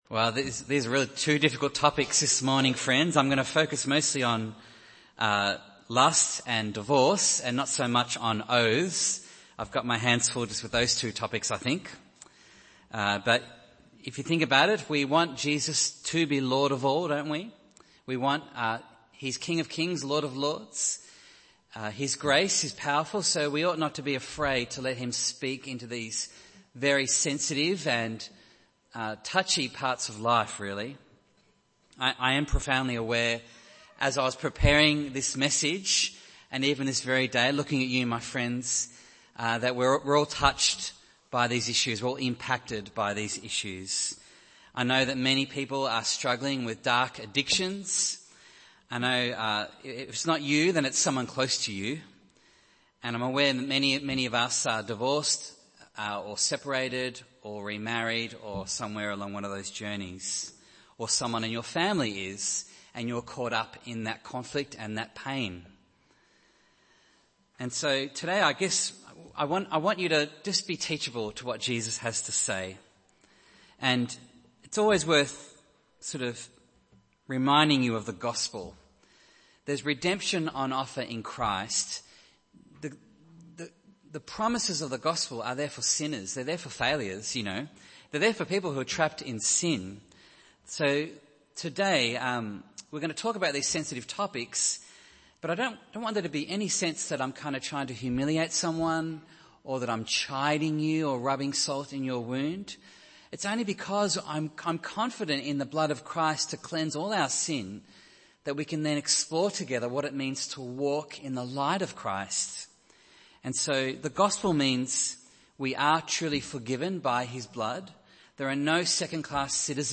Series: Sermon on the Mount – The Inverted Kingdom